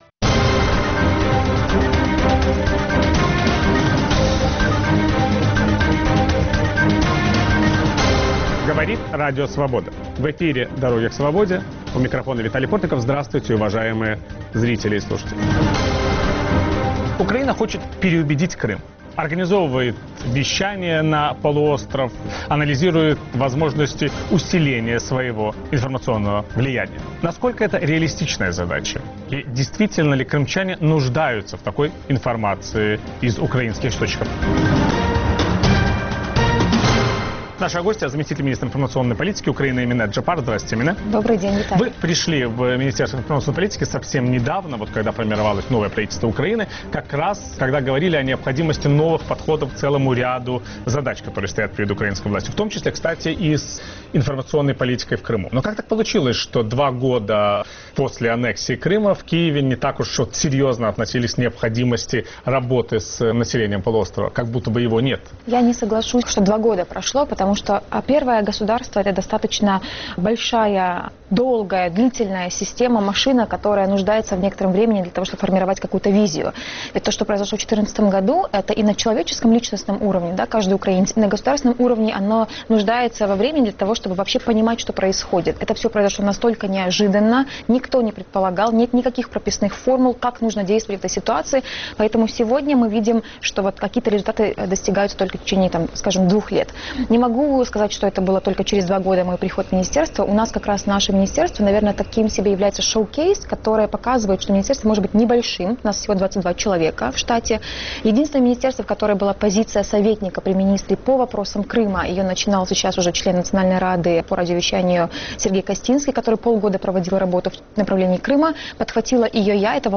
Как Украине говорить с Крымом и Донбассом? Собеседник Виталия Портникова - первый заместитель министра информационной политики Украины Эмине Джеппар